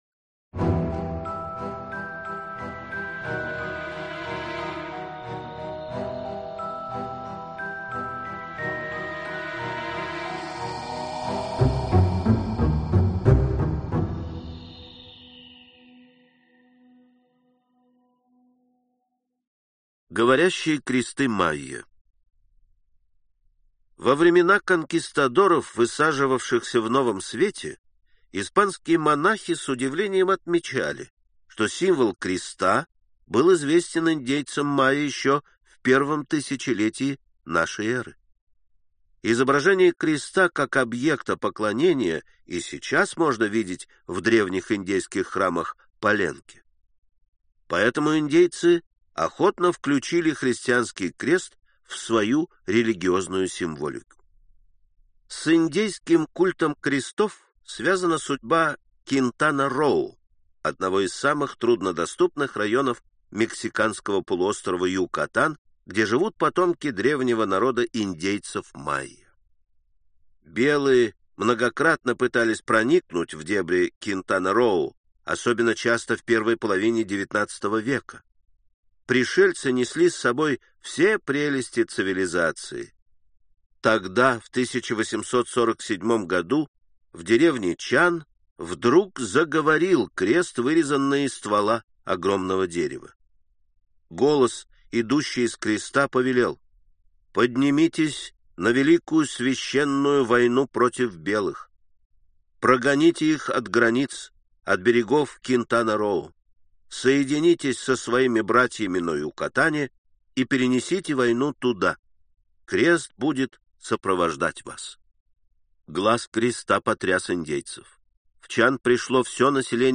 Аудиокнига Великие загадки прошлого | Библиотека аудиокниг